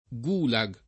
gulag [ g2 la g ; russo g u N# k ]